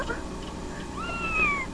Tama-Sama SPEAKS! (he sounds like a future leader of the country)